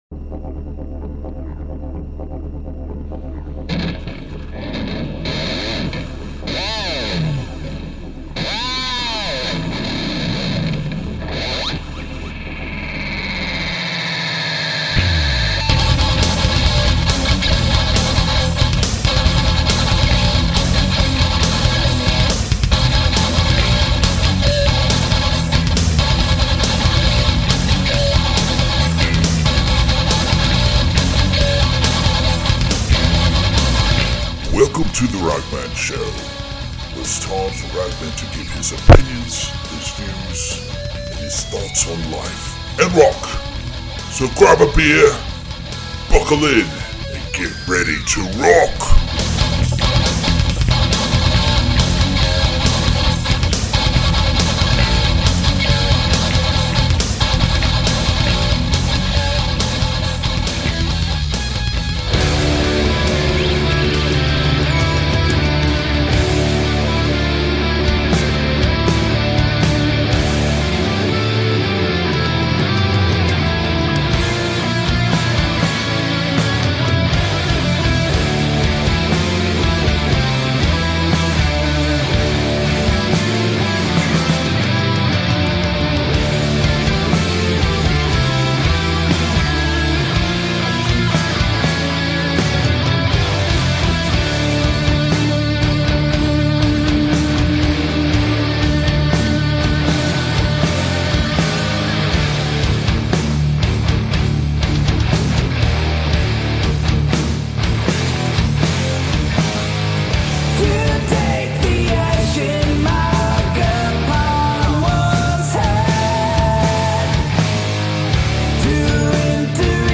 Ready for a guitar heavy show?